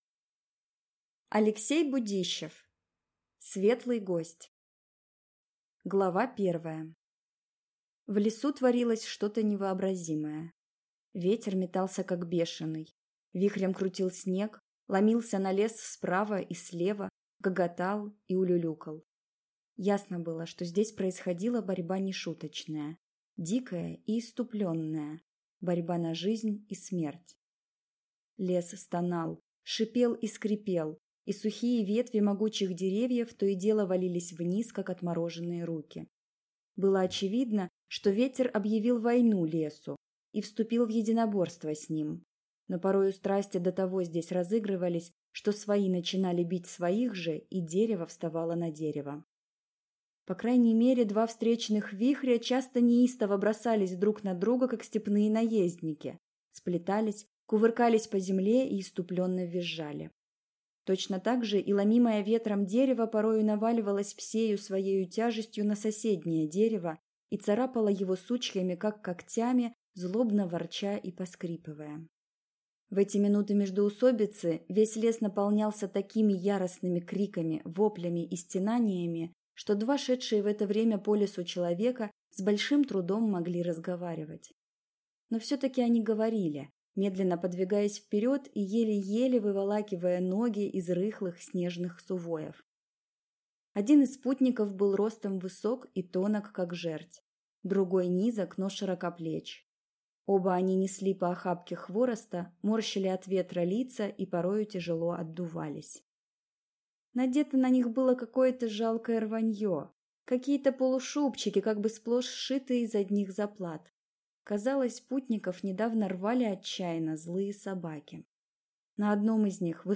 Аудиокнига Светлый гость